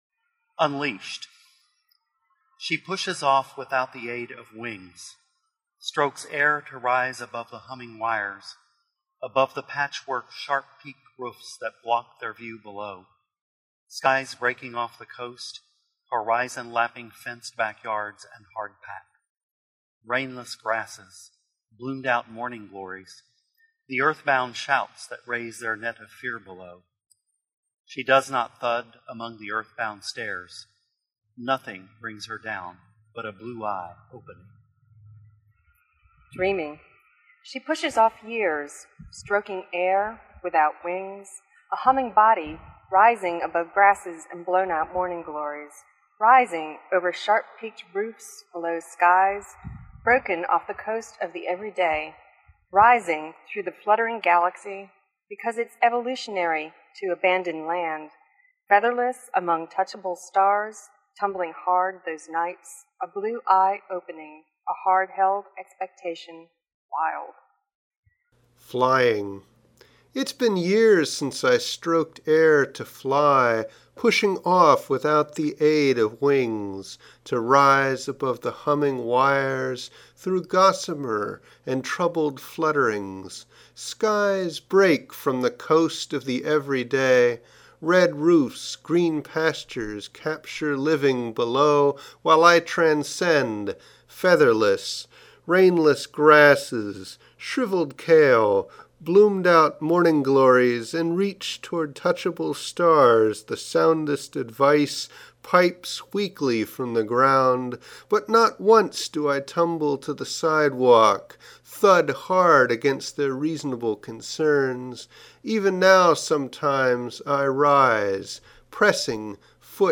Reading by the authors